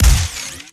GUNTech_Sci Fi Shotgun Fire_05_SFRMS_SCIWPNS.wav